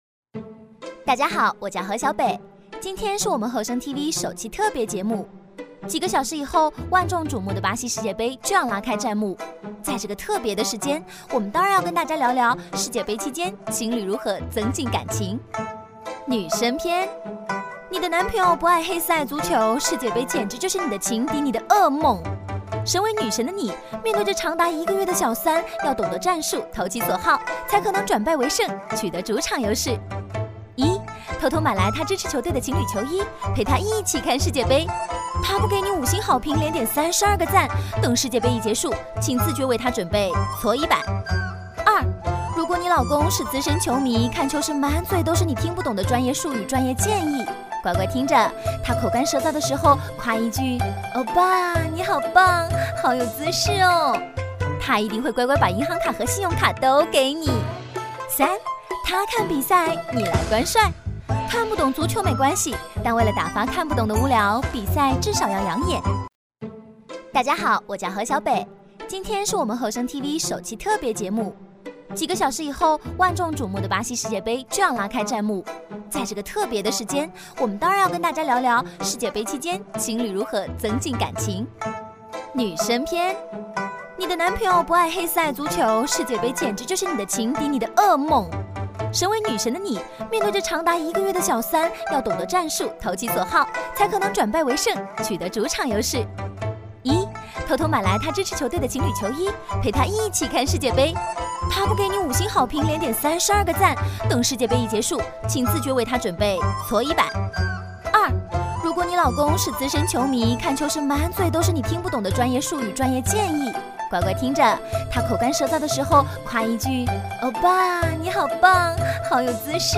• 女S155 国语 女声 飞碟说MG动画-世界杯观影指南-飞碟说-病毒、甜美、略带台湾腔 积极向上|时尚活力|亲切甜美|脱口秀